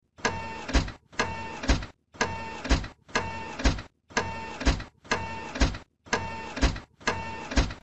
El efecto de sonido de un robot caminando
Descarga el sonido de un robot caminando y dale a tu proyecto un toque mecánico y futurista. Escucha cada paso metálico, el peso de sus engranajes y el ritmo preciso de su marcha.
Sonido-robot-caminando.wav